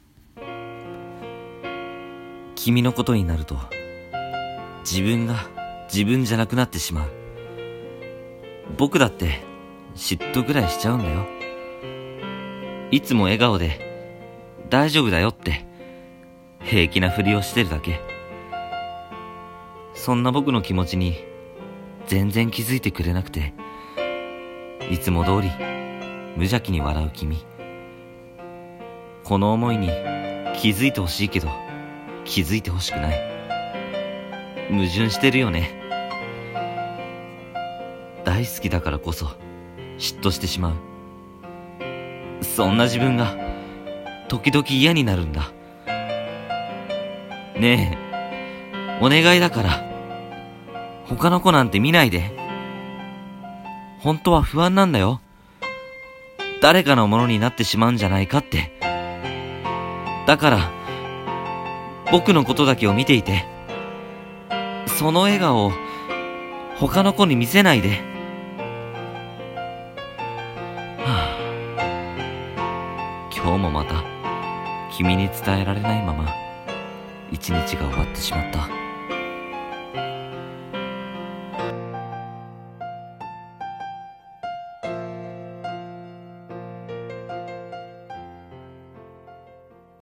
声劇/朗読【嫉妬 自分だけに笑顔を見せて…】